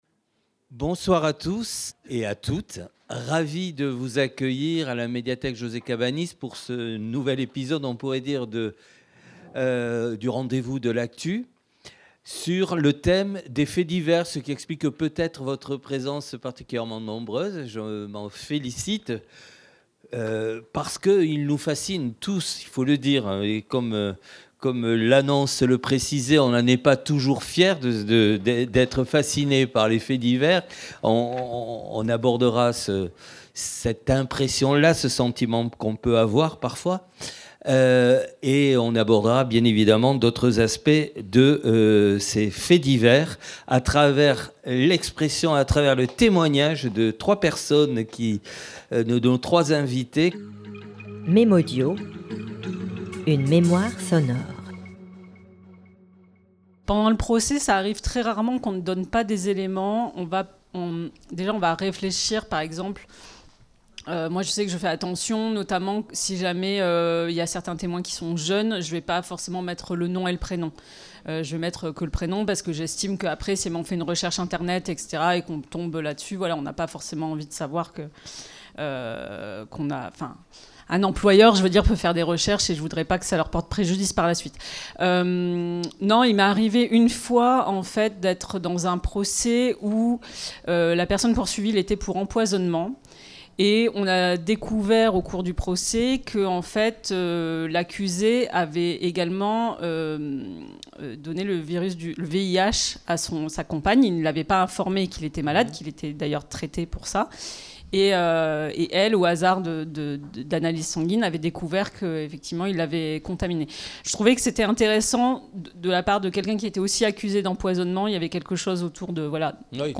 Table ronde autour de la fascination exercée par les faits divers, à la médiathèque José Cabanis, Toulouse le 19 mars 2026.
Table ronde
Journalistes, psychologues et avocats évoqueront certaines célèbres affaires et nous permettront dans un même élan d’y voir plus clair sur nous-mêmes.